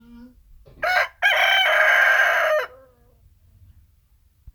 Listen to his cock-a-doodle-doo…
cockadoodledoo1.m4a